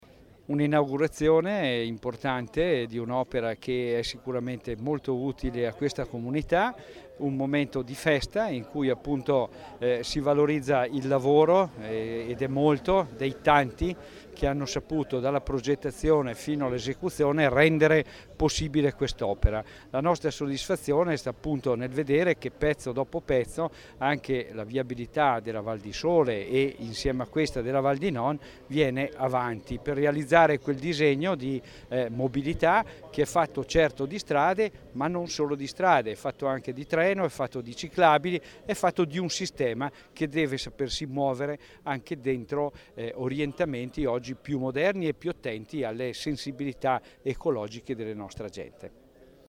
Stamattina in val di Sole la cerimonia a cui ha preso parte l’assessore provinciale Mauro Gilmozzi
Scarica il file Gilmozzi Inaugurazione SS 42_MP3 256K.mp3